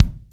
R.L KICK3.wav